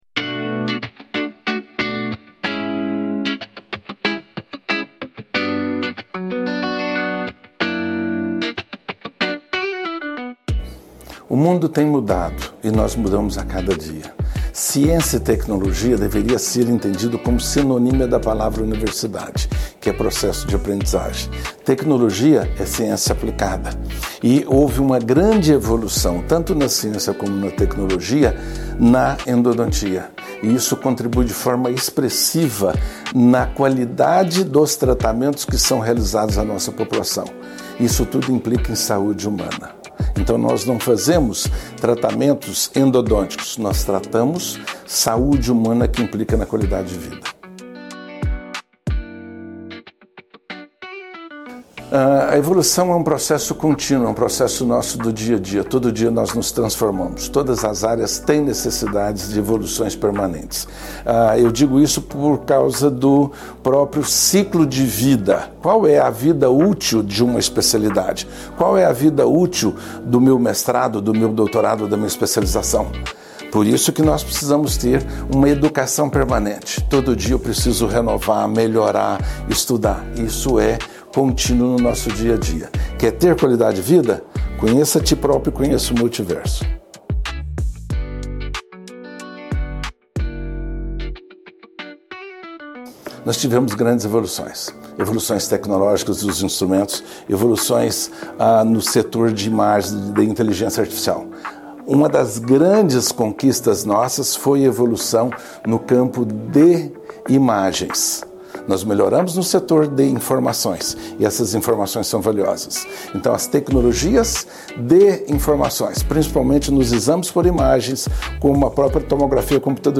Ciência e Tecnologia no Sucesso dos Tratamentos Endodônticos | Entrevista